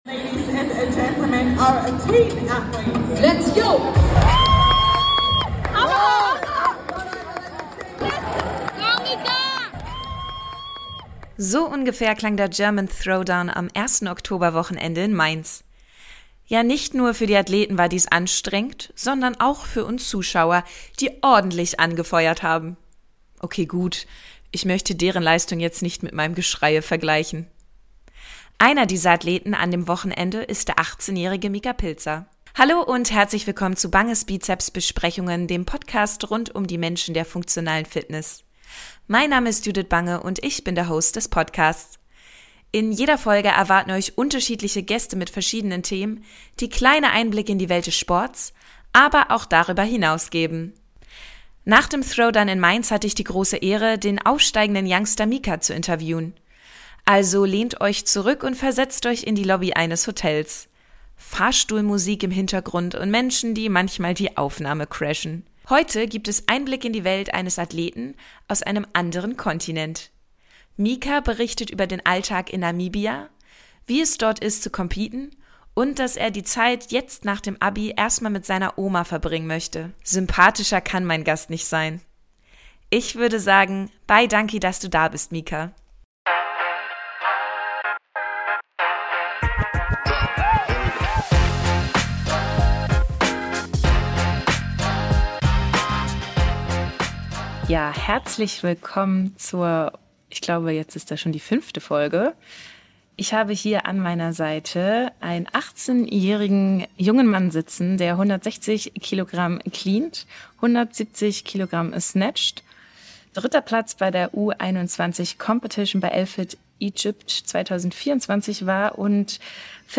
Wie Crossfit in Namibia ist und wohin es noch für ihn gehen soll, erfahrt ihr im Interview.